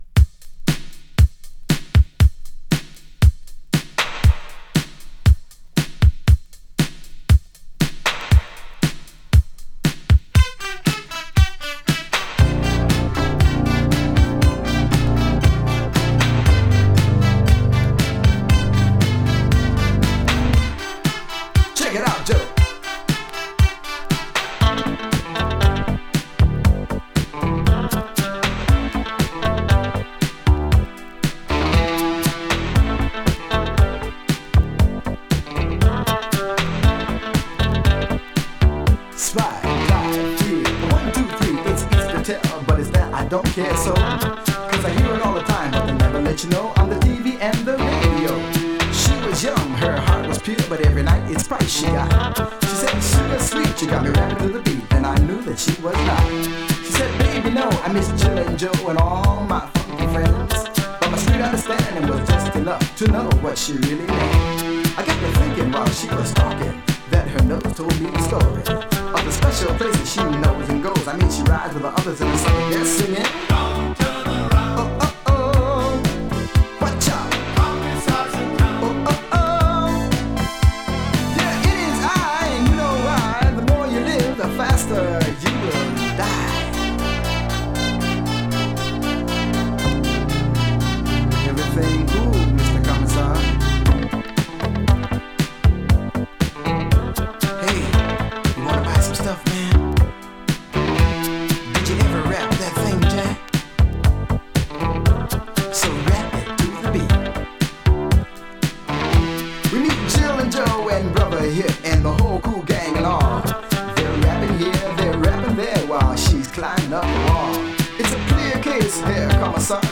[BOOGIE] [DISCO]
New wave disco!
Vocal